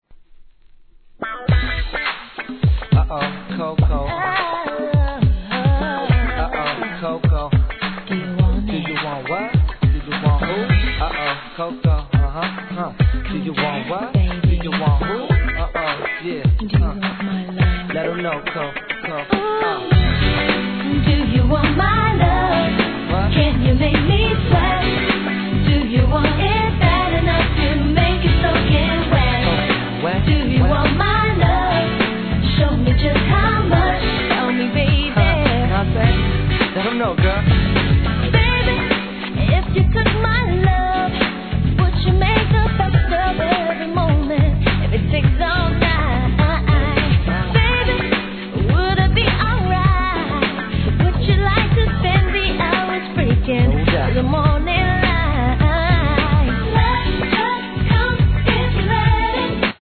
HIP HOP/R&B
キャッチー且つPOPなR&Bナンバーで、ここ日本でもMIXをはじめ大人気となった一曲!!